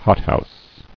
[hot·house]